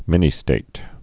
(mĭnē-stāt)